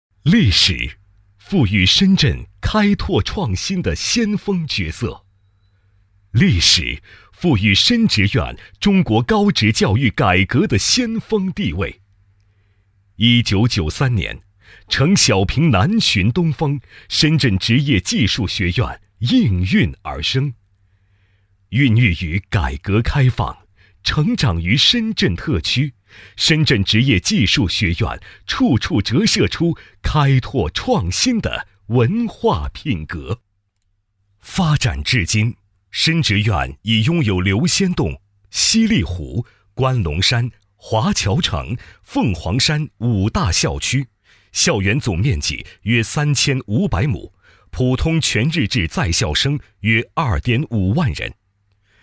男国443_广告_促销_富森美_激情-新声库配音网
配音风格： 稳重 成熟 大气 力度 激情 抒情 温情 活力